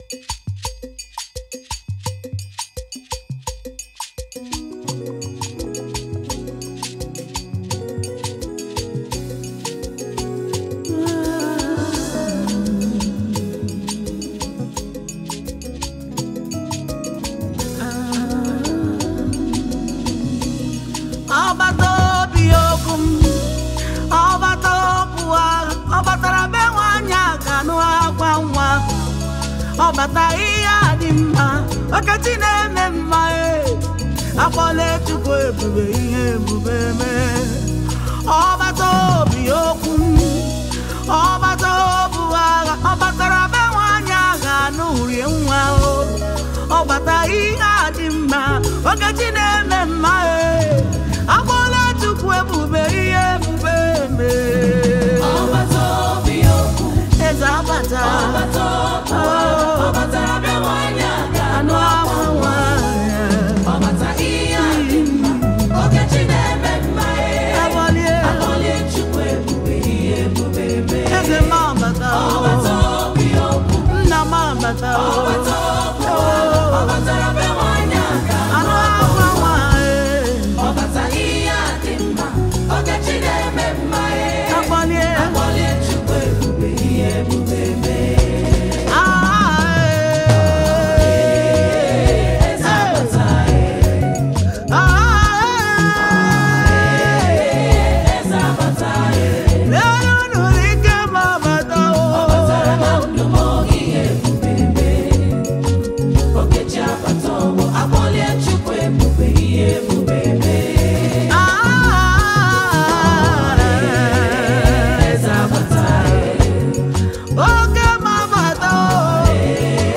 Talented Nigerian gospel singer and songwriter